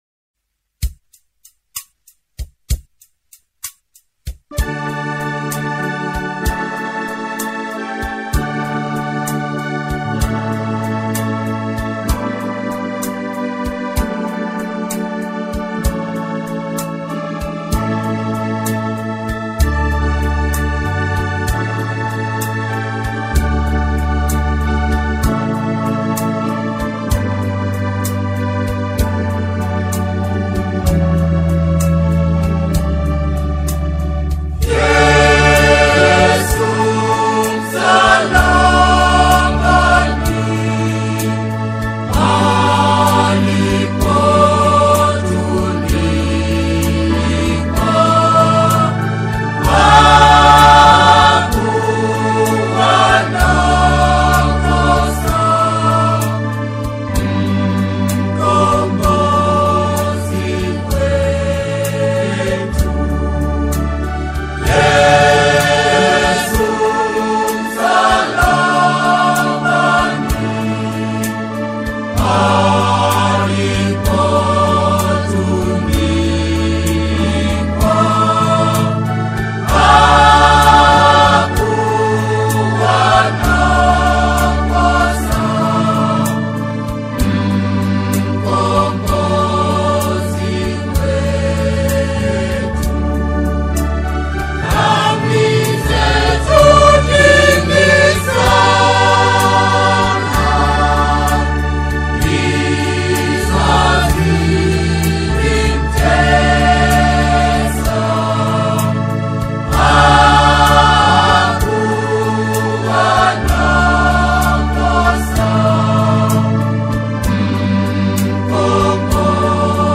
gospel song